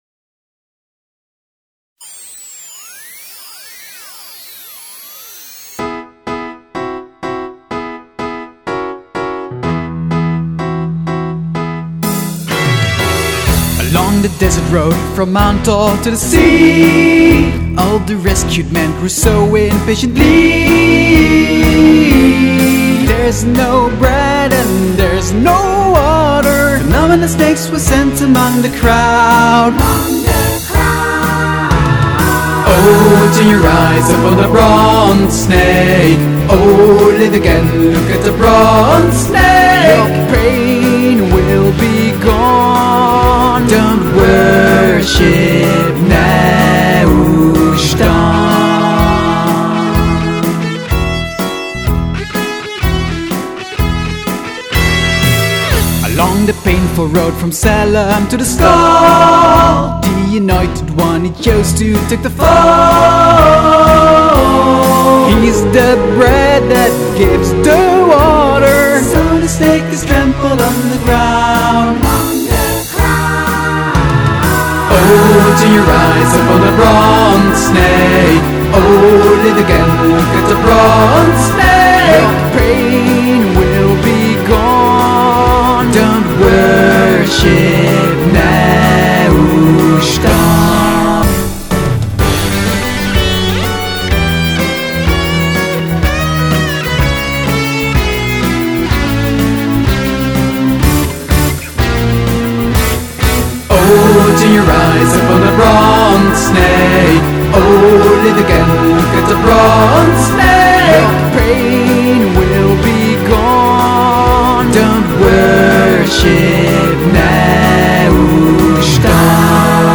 Deze song heb ik opnieuw opgenomen en ingezongen!
toetsen en gitaren
zang en koortjes